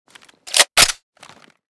fn2000_unjam.ogg.bak